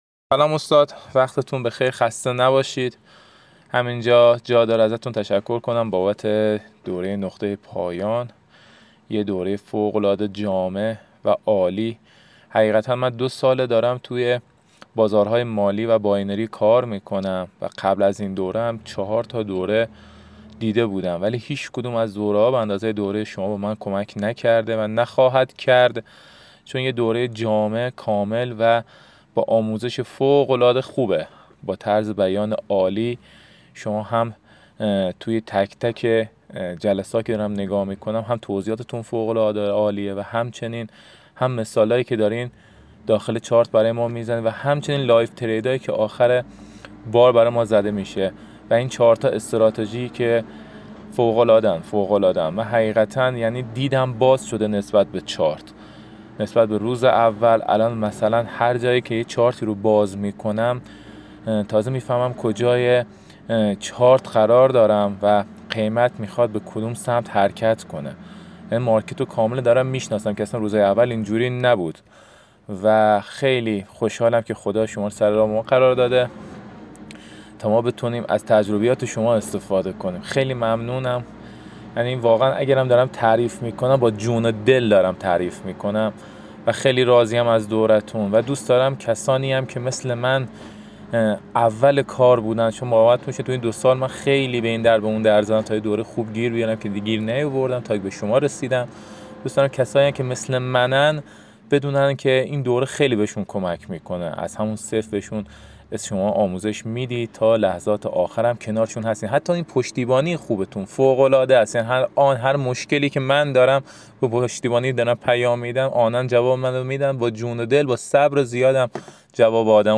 صدای دانشجویان دوره :